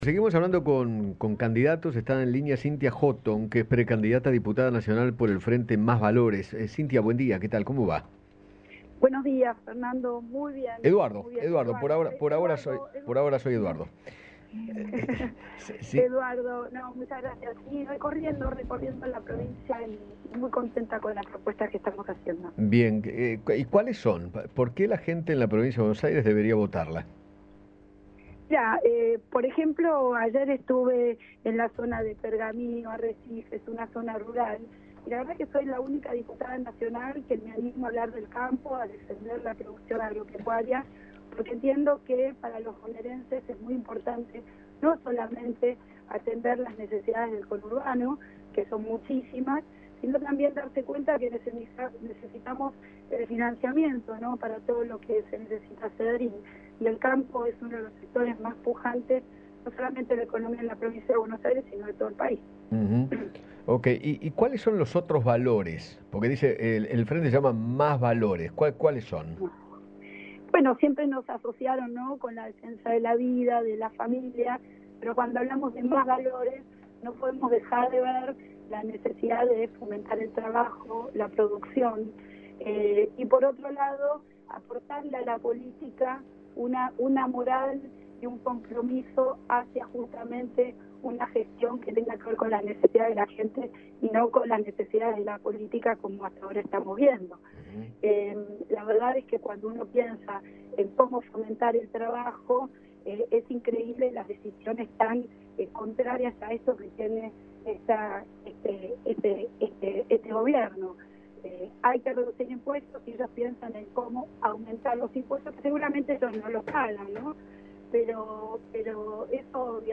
Cynthia Hotton, precandidata a diputada nacional, conversó con Eduardo Feinmann sobre las declaraciones de la ministra de Seguridad acerca de Suiza. Además, detalló sus principales propuestas.